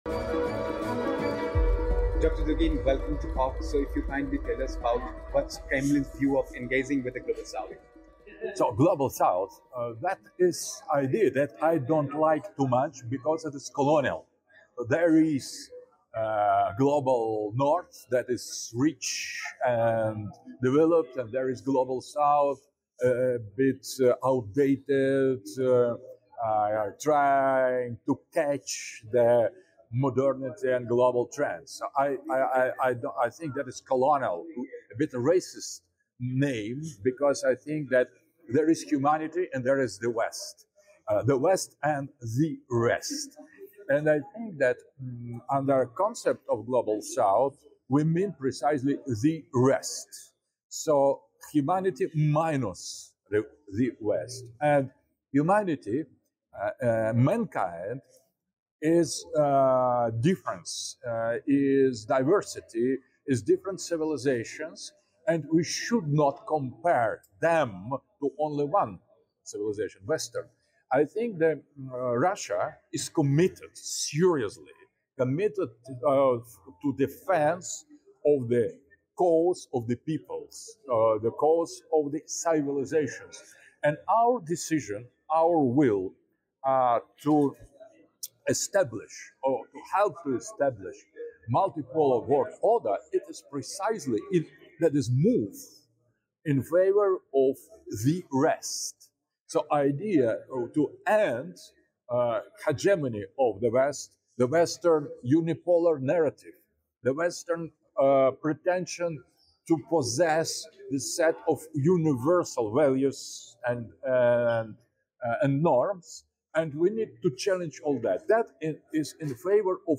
In this insightful discussion with COGGS, acclaimed Russian political philosopher Alexander Dugin shares his perspective on the Global South and its significance. Explore Russia's views on the Global South, the traditional values that shape this region, and the contrasting dynamics between the West and the Rest of the world.